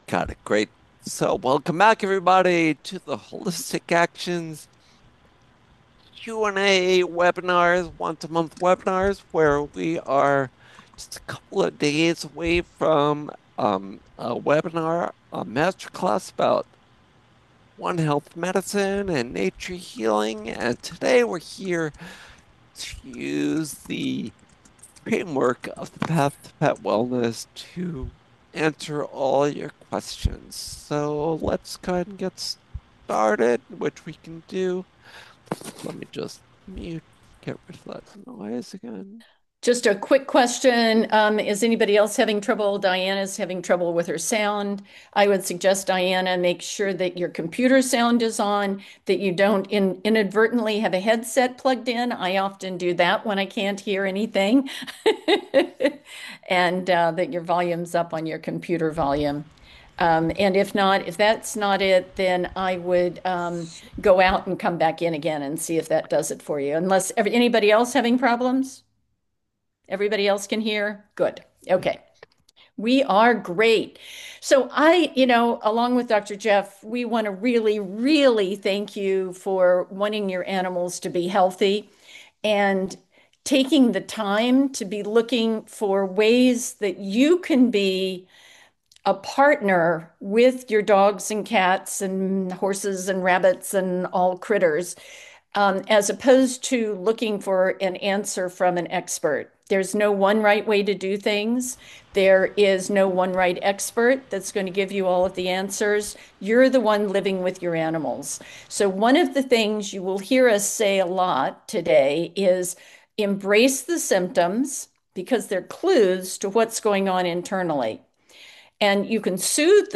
Ask A Vet – Live Q&A 01/30/26 - Holistic Actions!